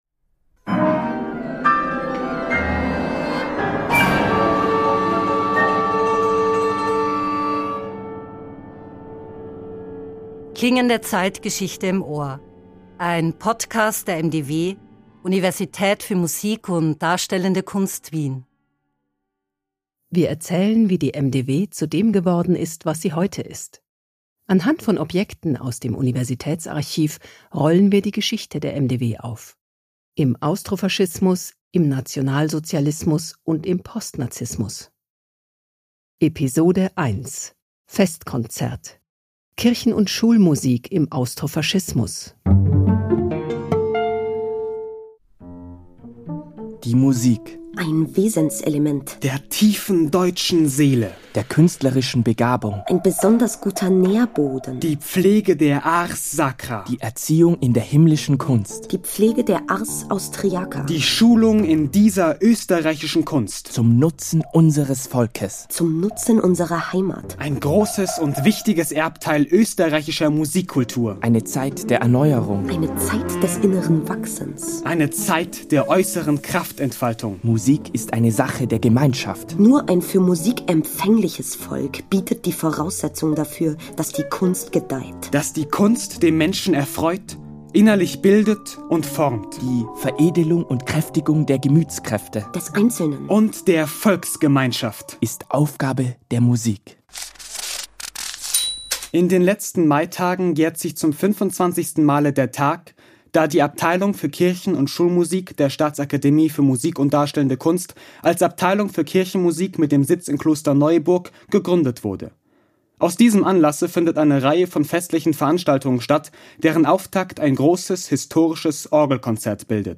Jede Folge verschränkt Collagen aus Originaldokumenten, die als szenischer Text arrangiert und eingelesen werden, mit zeitgenössischen Originalkompositionen und Sounddesign. Die unterschiedlichen Tonlagen aus Vergangenheit und Gegenwart werden von Komponist:innen weiterentwickelt, von Schauspiel-Studierenden werden sie zur Sprache gebracht.